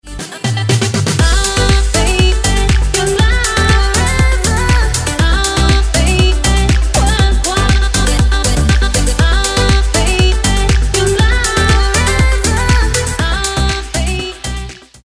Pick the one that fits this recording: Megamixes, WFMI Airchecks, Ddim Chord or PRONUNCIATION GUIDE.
Megamixes